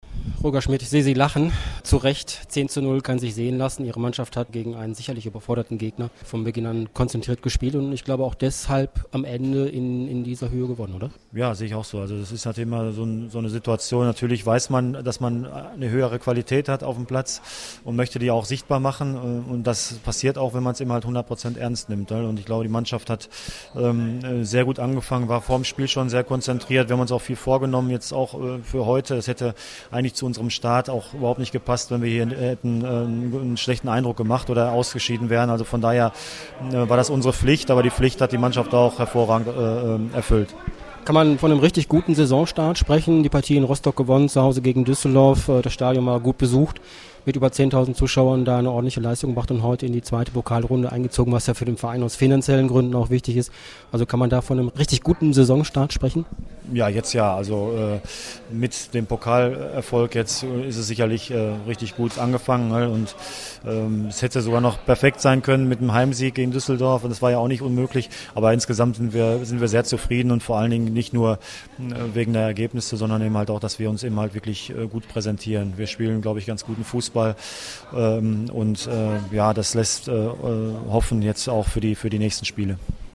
AUDIOKOMMENTAR
Chef-Trainer Roger Schmidt zum Spiel
Ahlen_Interview_Schmidt.mp3